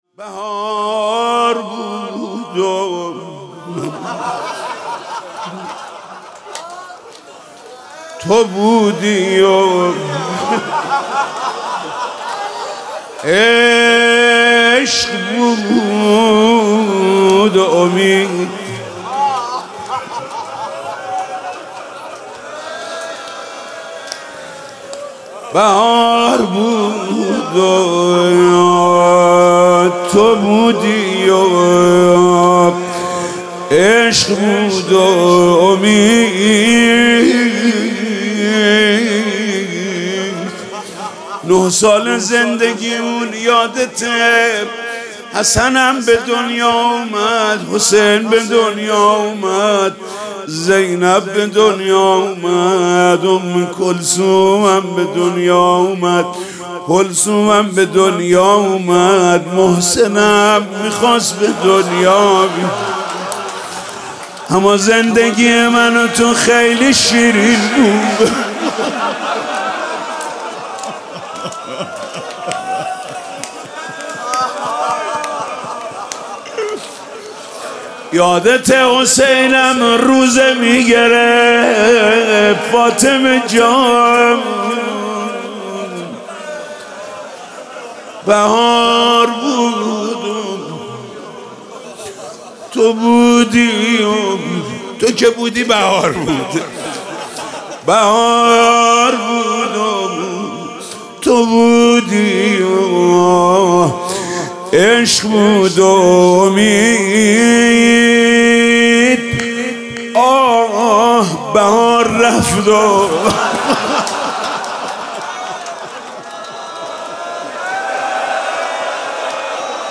روضه جانسوز
با نوای دلنشین